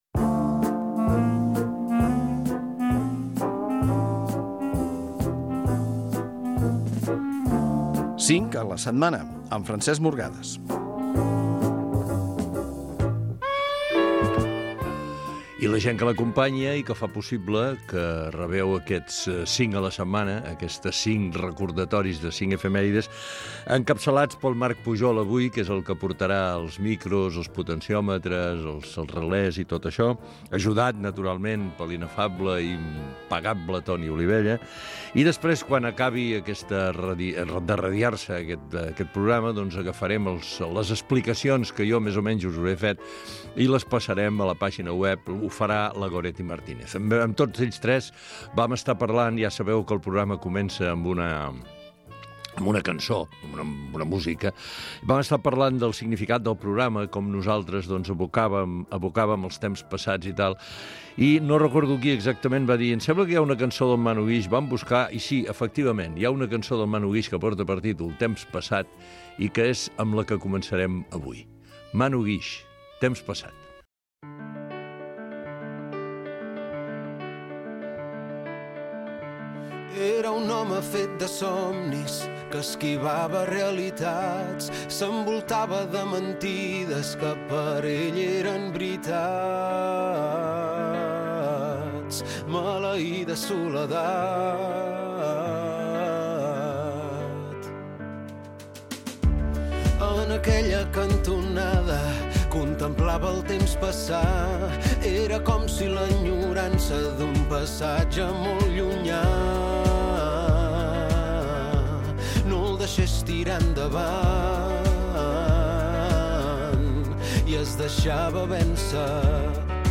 Efemèrides i música